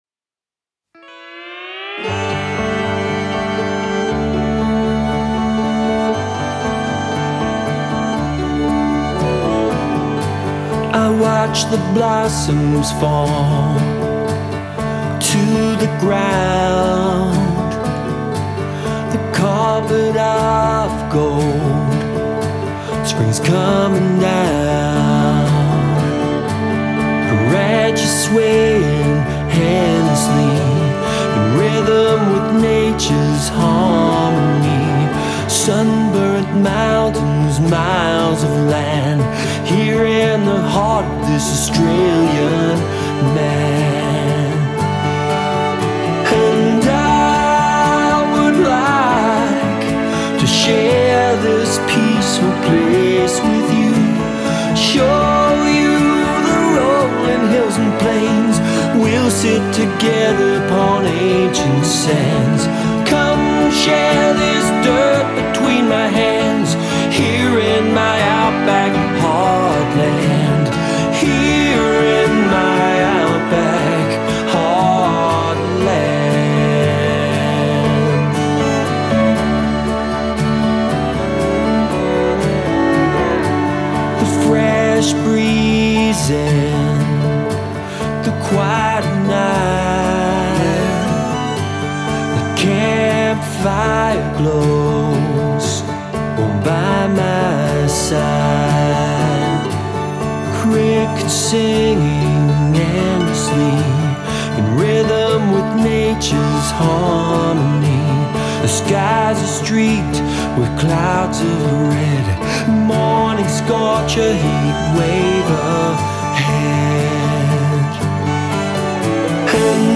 modern contemporary Australiana ballad